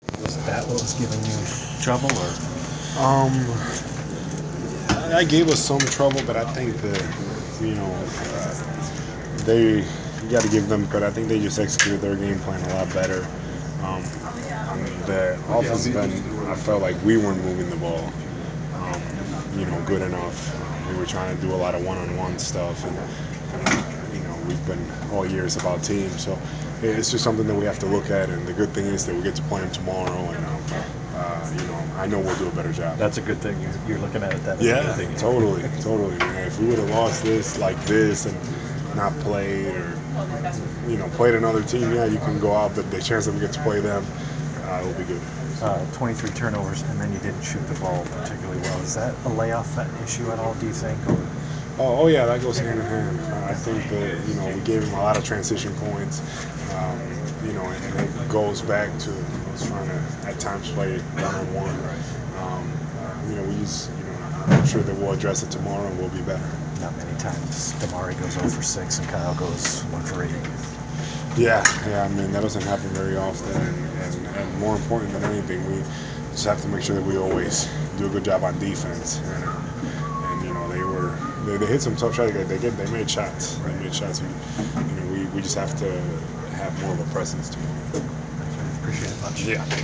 Inside the Inquirer: Postgame presser with Atlanta Hawks’ Al Horford (12/26/14)
We attended the post-game presser of Atlanta Hawks’ center Al Horford following his team’s 107-77 home defeat to the Milwaukee Bucks on Dec. 26. Horford had 13 points, five rebounds, two assists and two blocks in the loss.